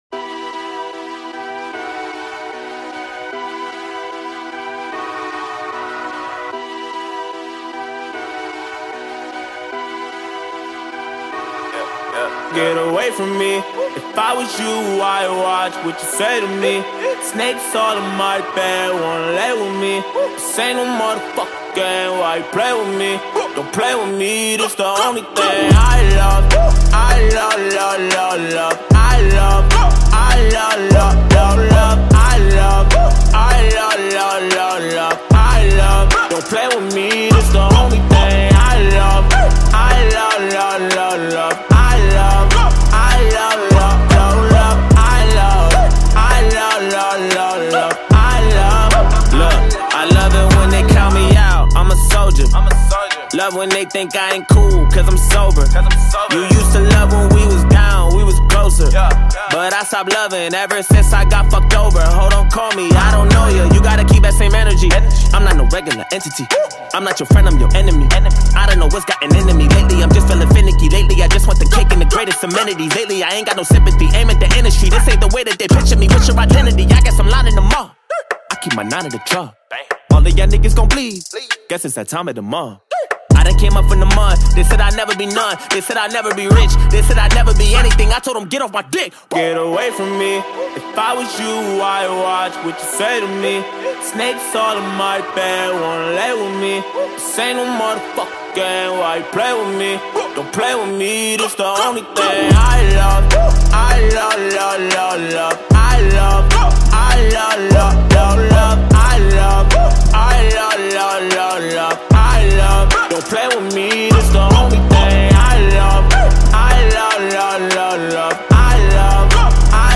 Rap